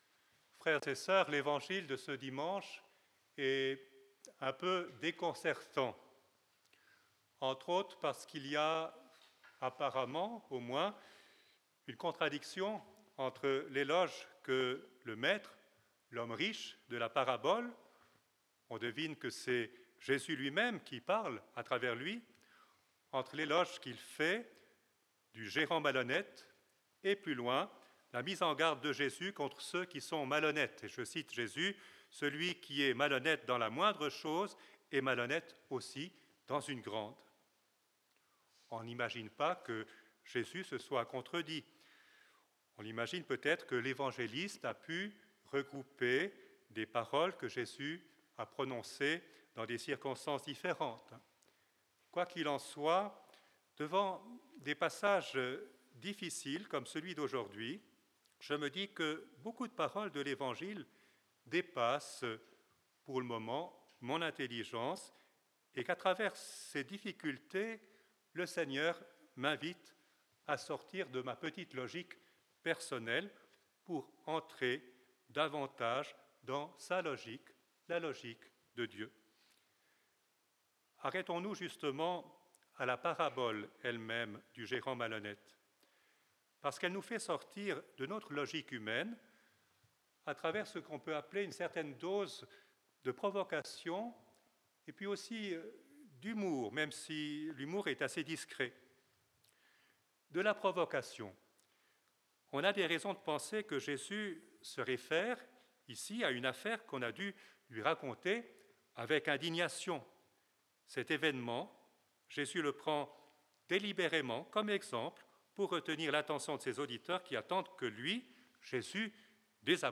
L'homélie